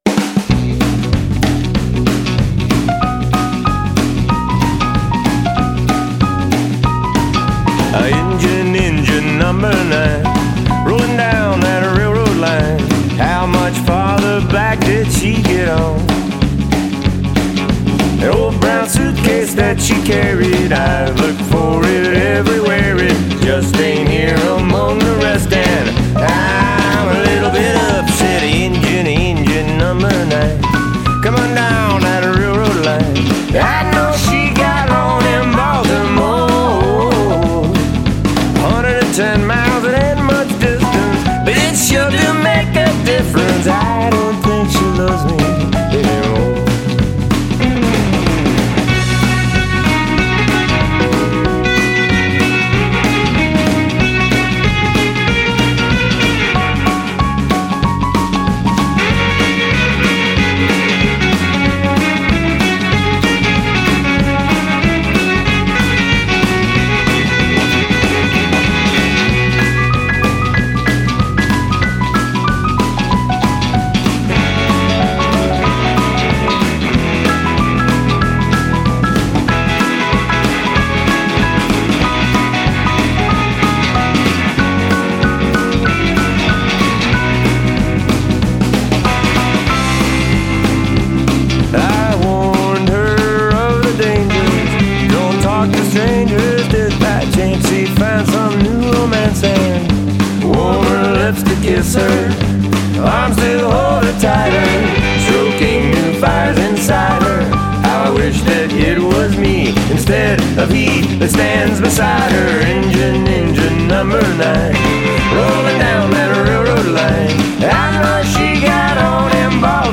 hillbilly surf rock